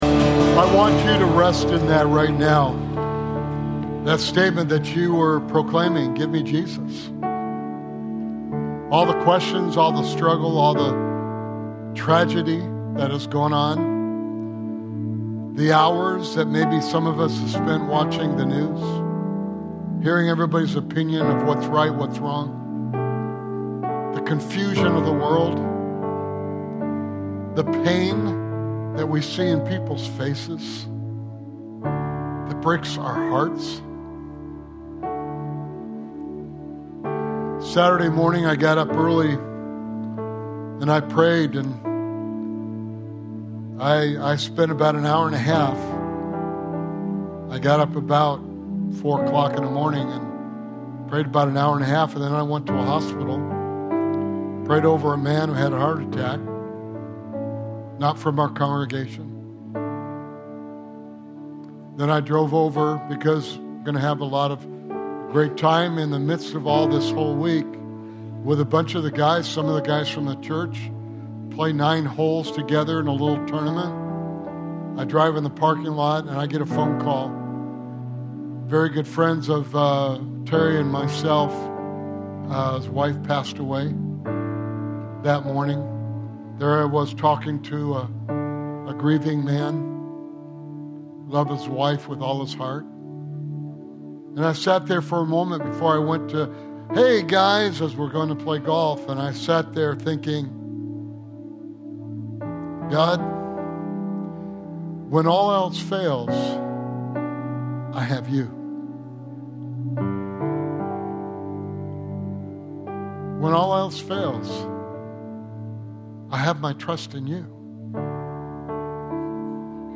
A Sunday morning sermon podcast from Valley Community Church in El Monte, CA.